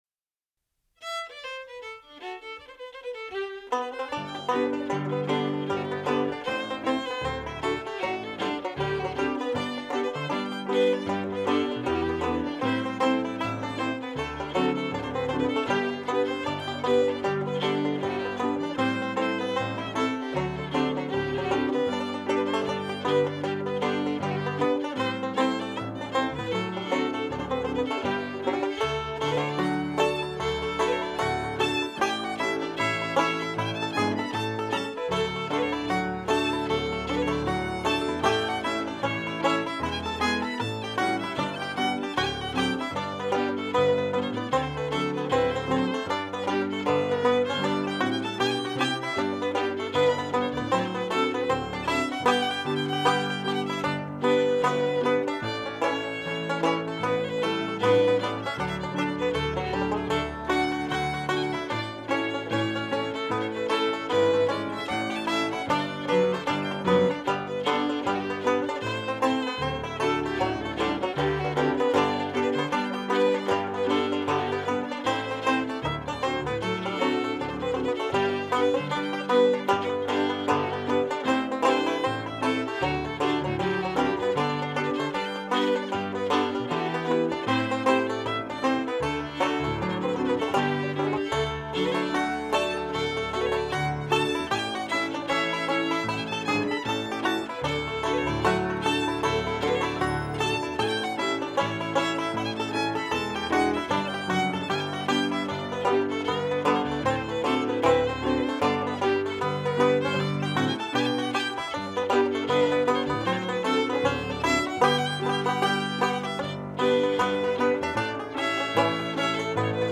Mrs. Galvin's Barndance | The Séamus Connolly Collection of Irish Music